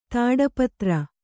♪ tāḍapatra